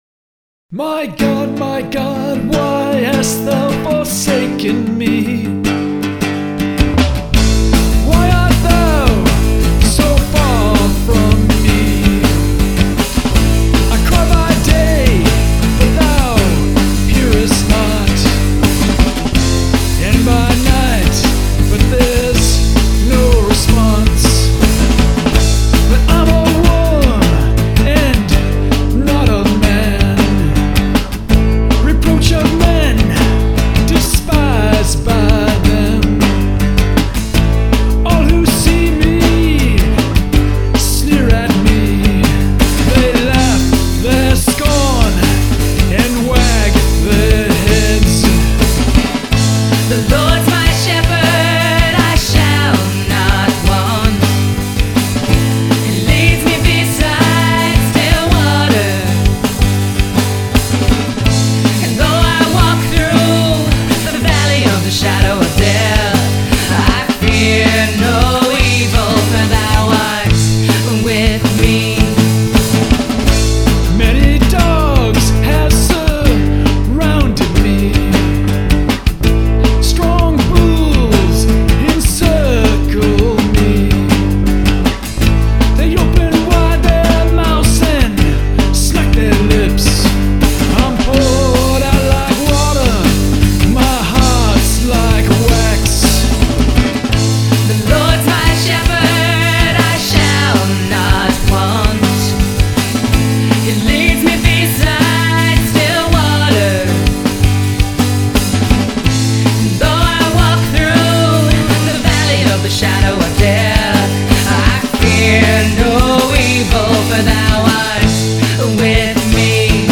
Guitar, Vocals, Bass, Harmonica
Drums
Keyboards